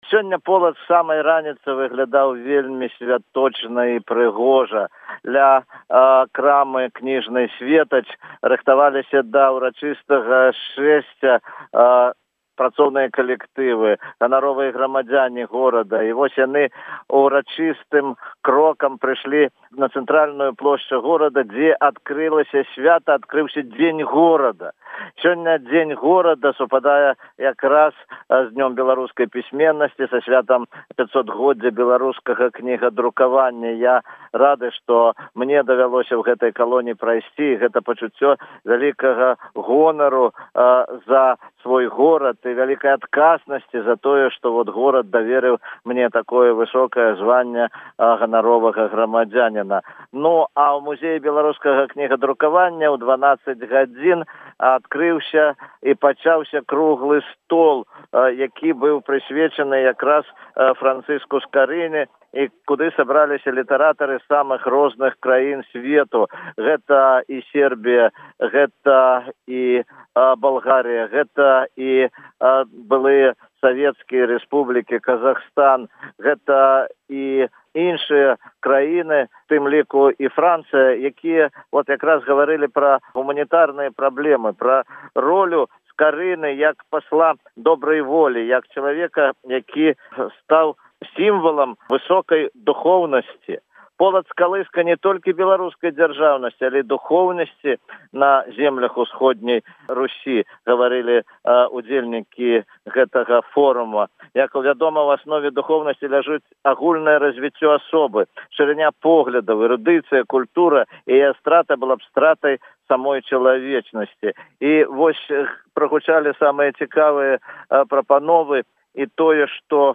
З падрабязнасцямі з Полацку